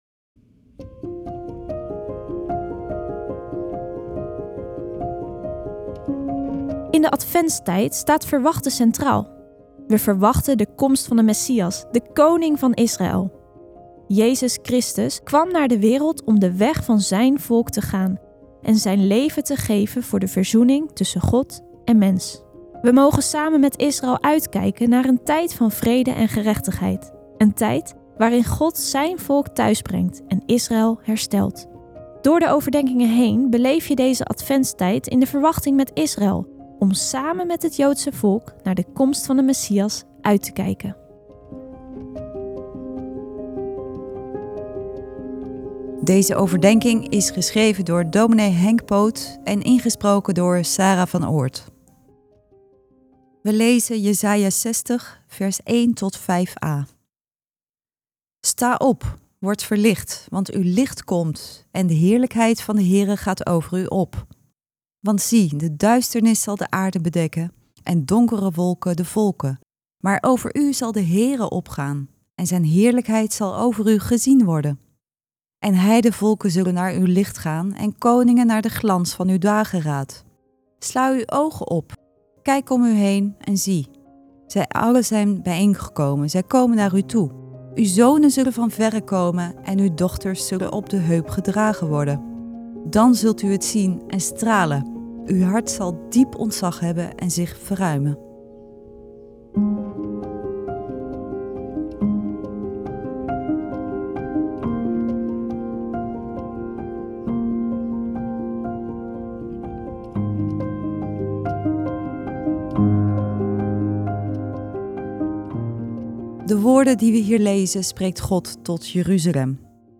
Elke dag luister je een Bijbelgedeelte met daarbij een korte overdenking.